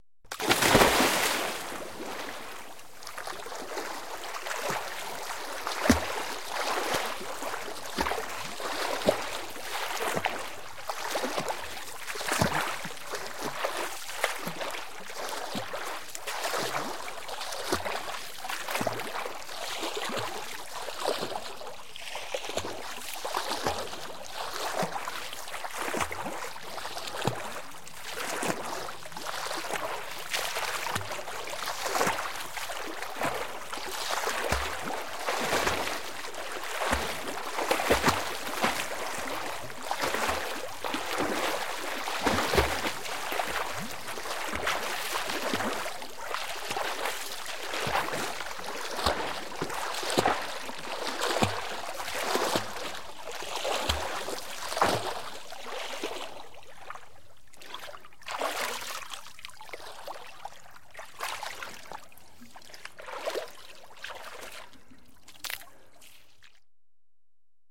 Звуки плавания
Звук плавания человека в бассейне